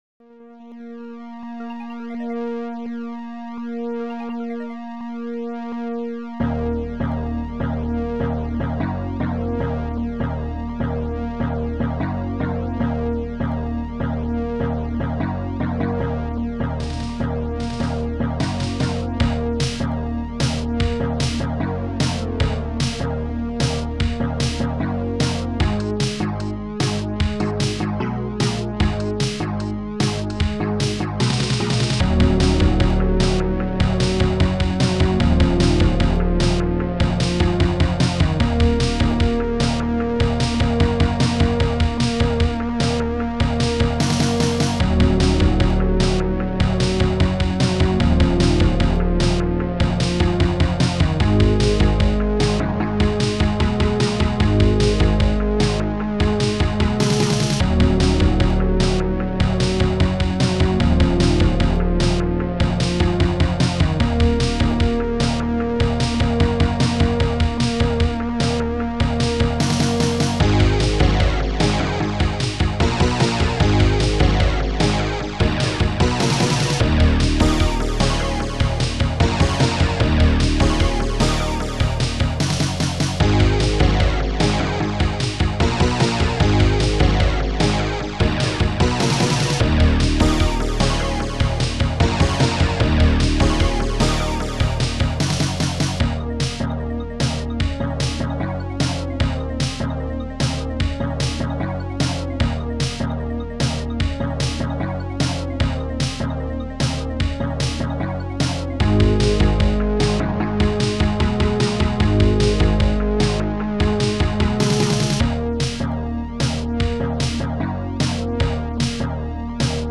Protracker and family
lead2
phasebow
punchy-bass
snared